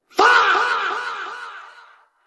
actually good fahhhh sfx Meme Sound Effect
actually good fahhhh sfx.mp3